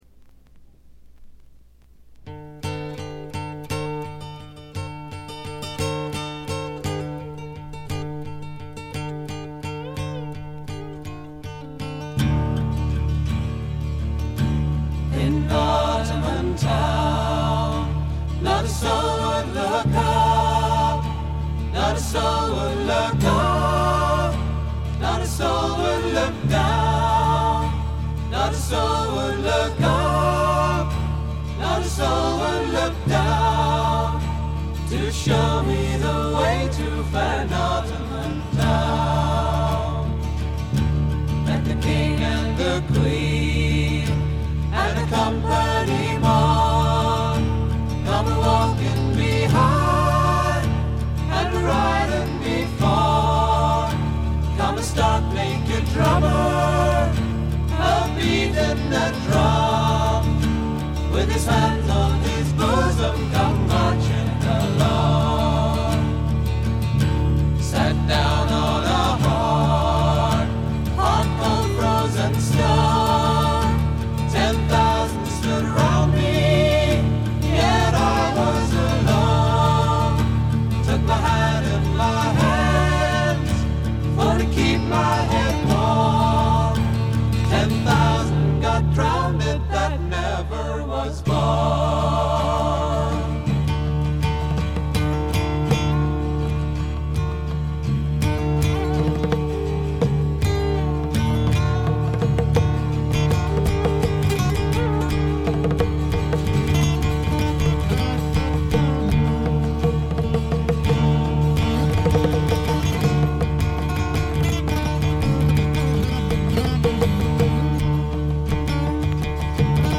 極めて良好に鑑賞できます。
英国フォークロック基本中の基本。
試聴曲は現品からの取り込み音源です。